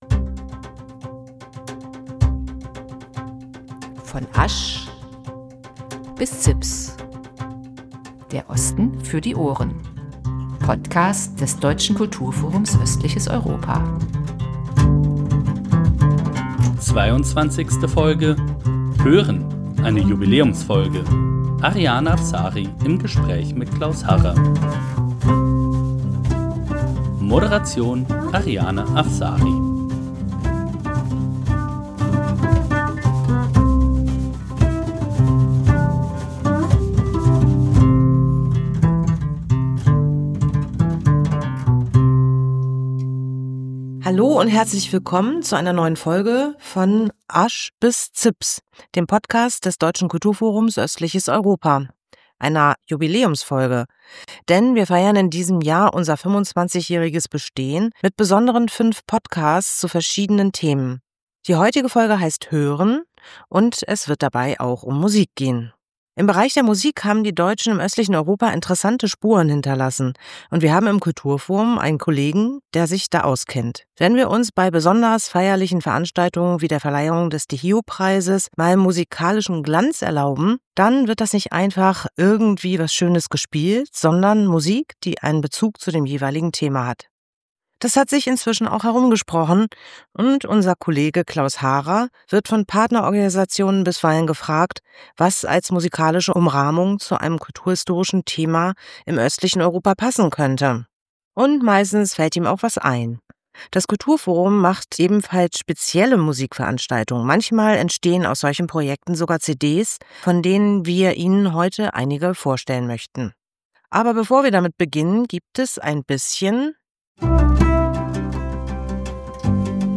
Und da im Rahmen dieser Projekte auch verschiedene CDs produziert wurden, gibt es in dieser Folge auch Musik aus dem östlichen Europa zu hören.